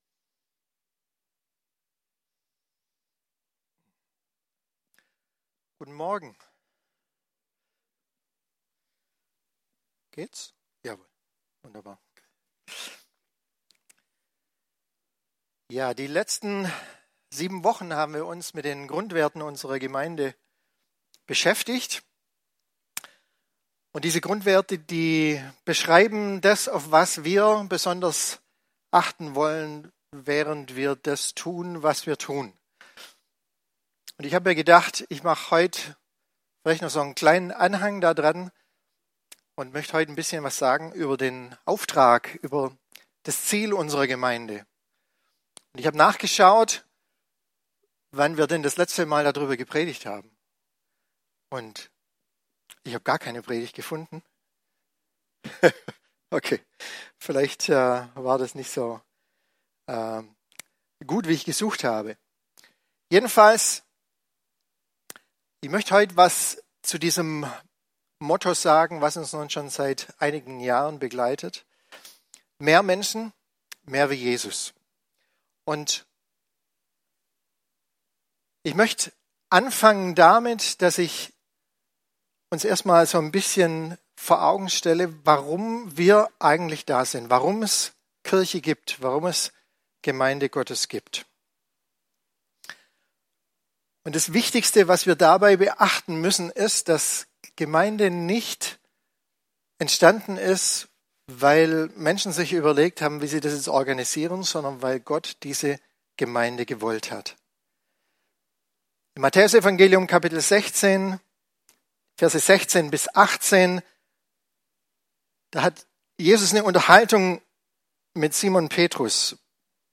Predigt-Details - FCG Ecclesia Laupheim